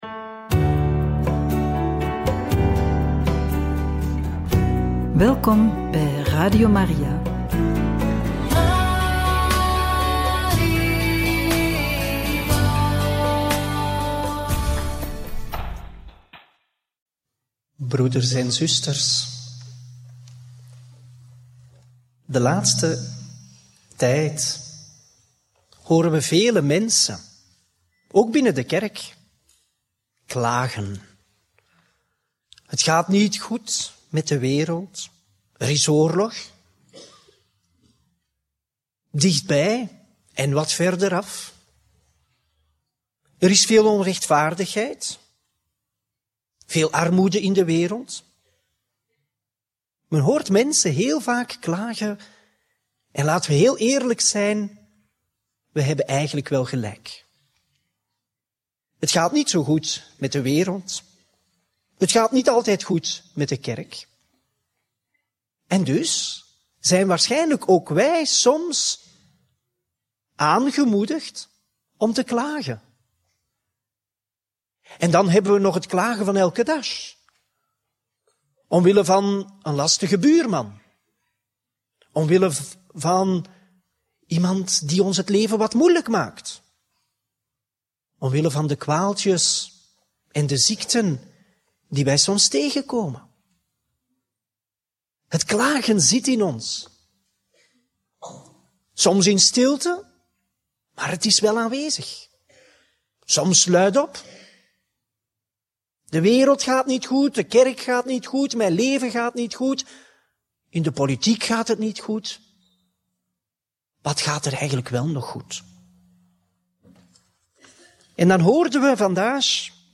Homilie op het feest van Maria bezoek, tijdens de bedevaart naar Banneux op 31 mei 2024 – Radio Maria